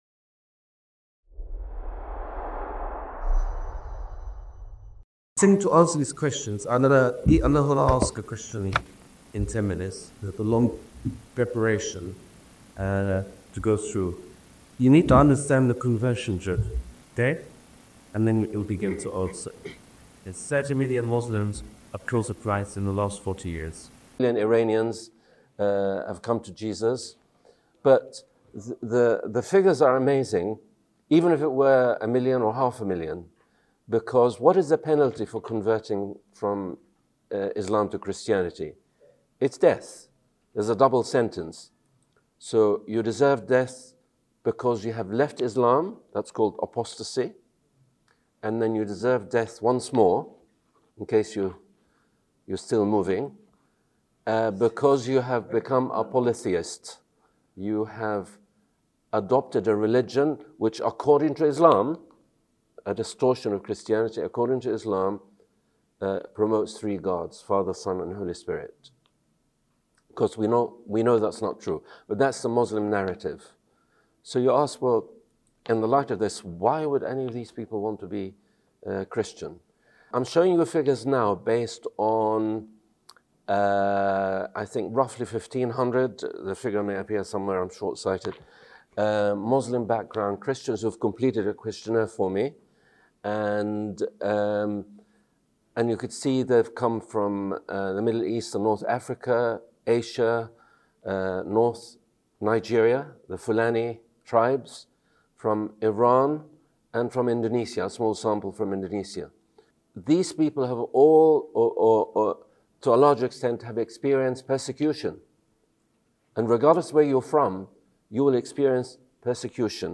This talk examines questionnaire-based research results from almost 1,600 believers from Muslim backgrounds.
Event: ELF Workshop